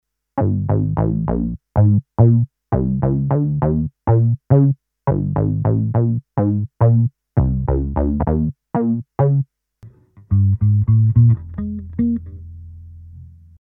It sounds the same, but plays differently.
The envelope is ADSR (restart) instead of env mode. This allows you to play very hard to get good tracking, without the sound changing volume.
(Sound sample is patch first, then bypassed)